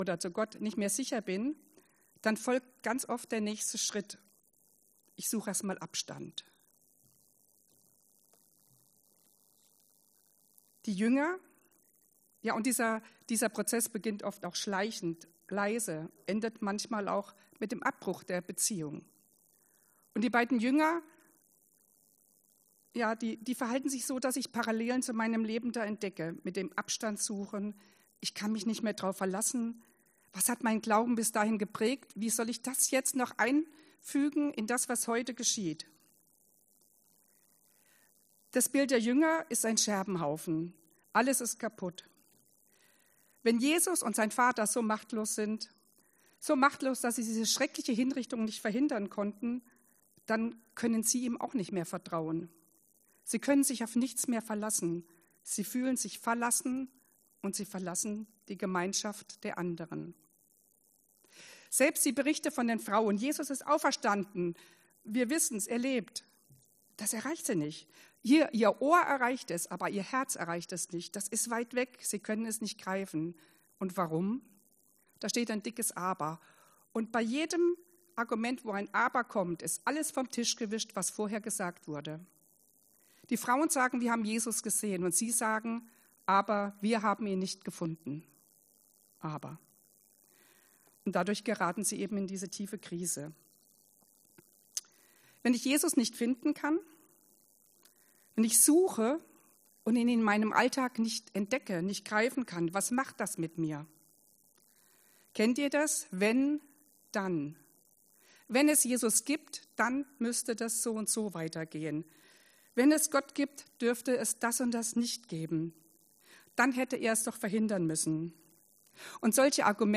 Auf dieser Seite findest du unsere Predigten der letzten 18 Jahre.